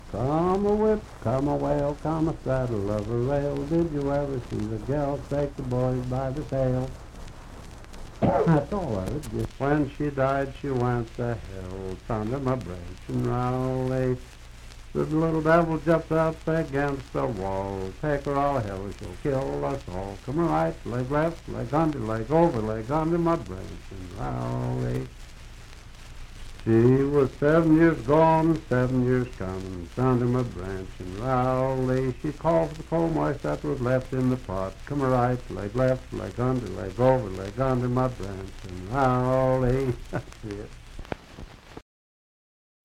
Unaccompanied vocal music
Voice (sung)
Marion County (W. Va.), Fairview (Marion County, W. Va.)